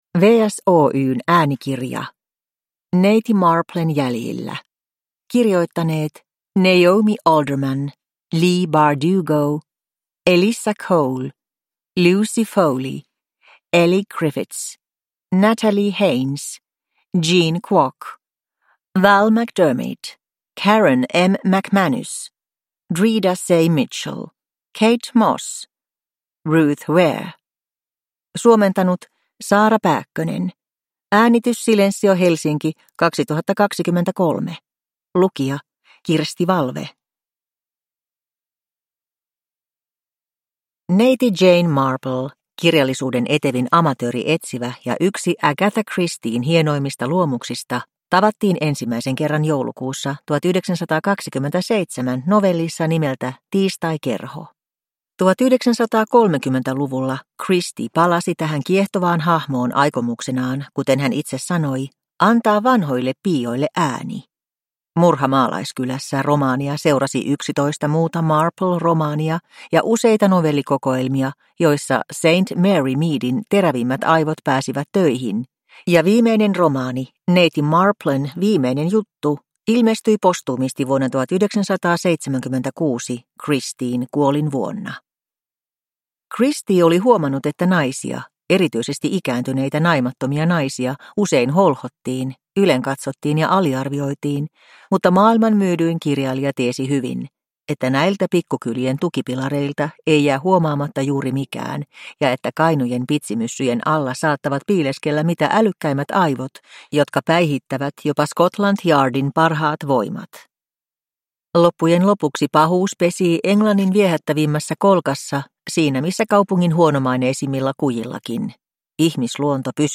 Downloadable Audiobook